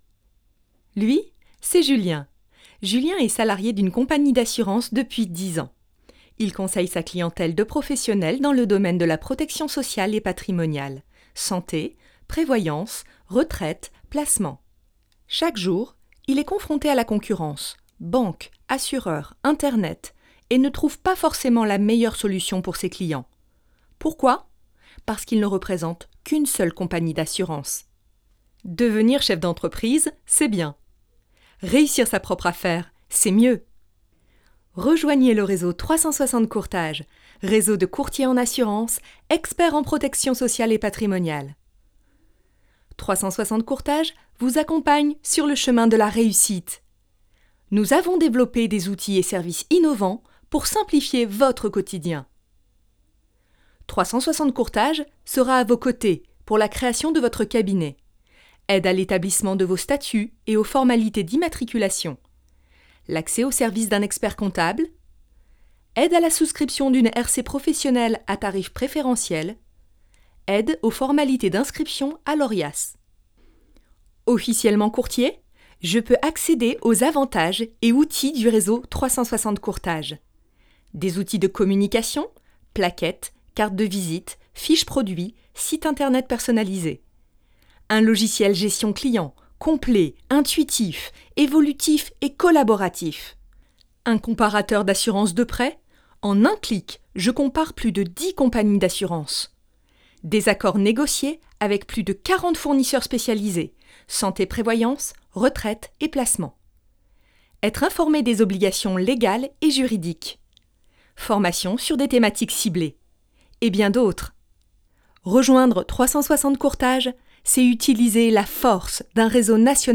Voix off | HD
INSTITUTIONNEL